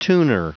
Prononciation du mot tuner en anglais (fichier audio)
Prononciation du mot : tuner